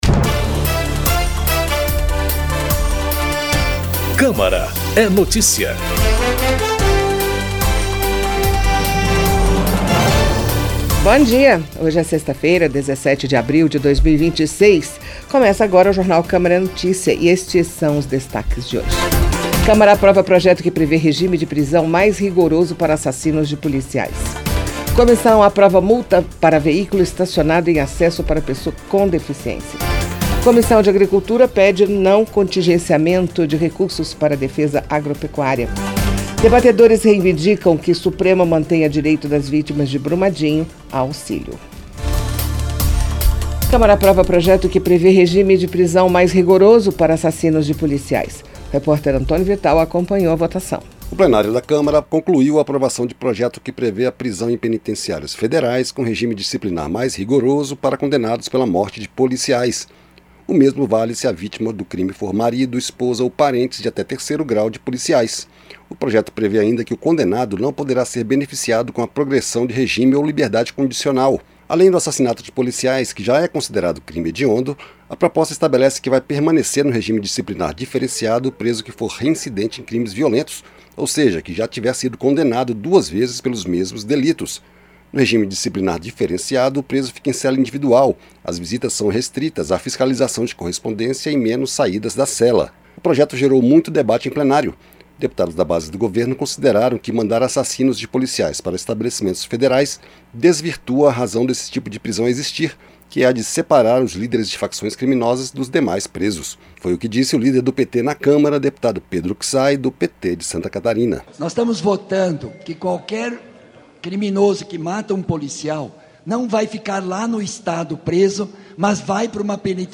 Jornal com as últimas notícias da Câmara dos Deputados